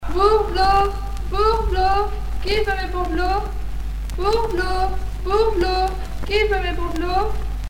Cris de rue de la marchande de bourneaux
Bayeux
Normandie